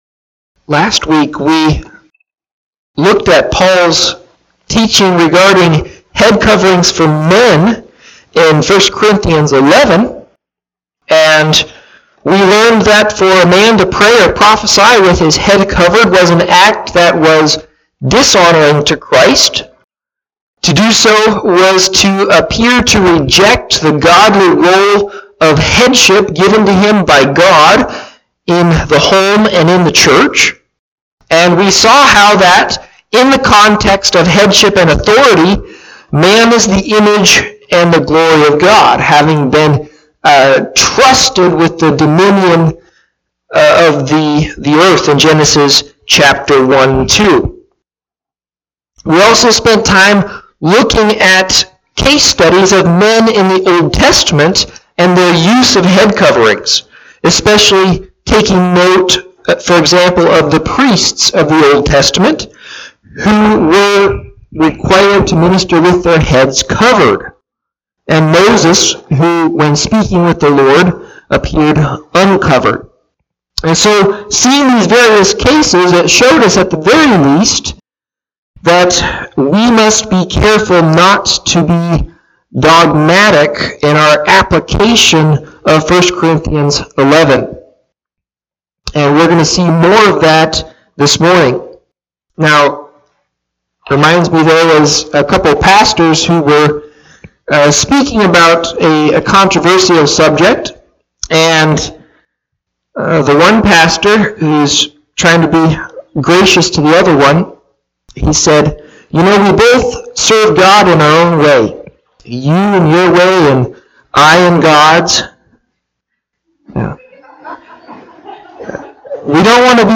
Listen to Audio of the sermon or Click Facebook live link above.